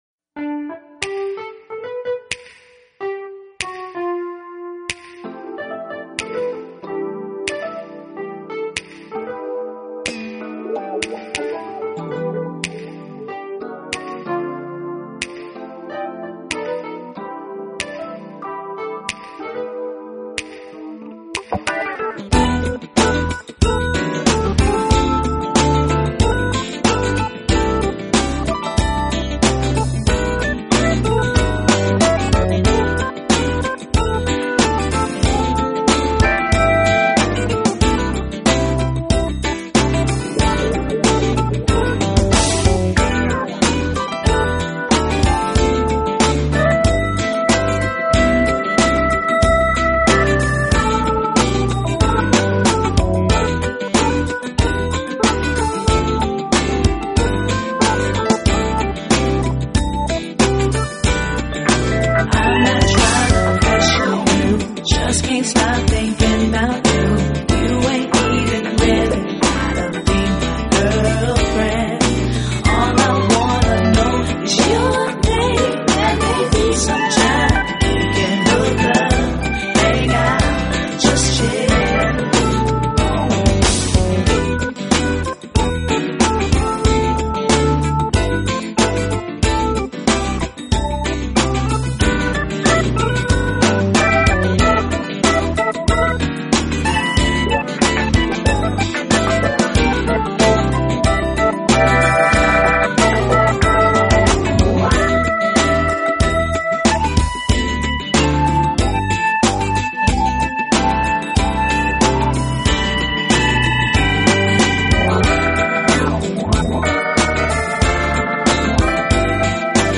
Genre: General Jazz
旋律清新流畅，技巧高超娴熟